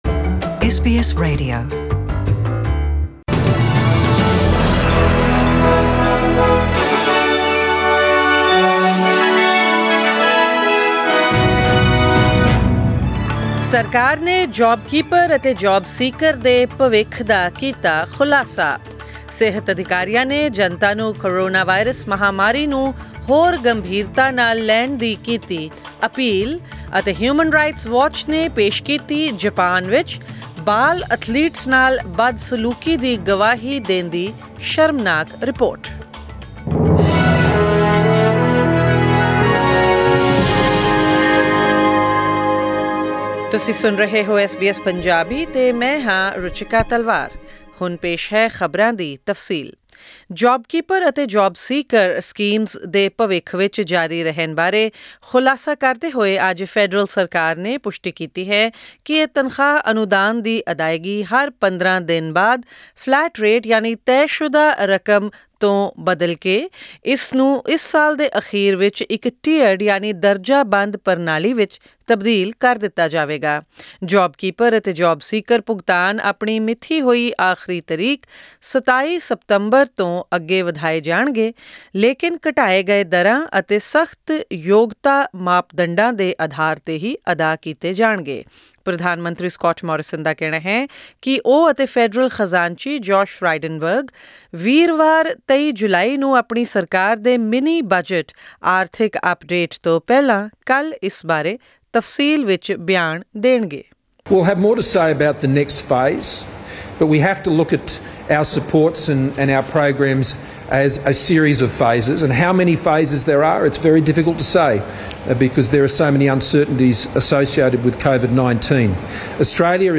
Australian News in Punjabi: 20 July 2020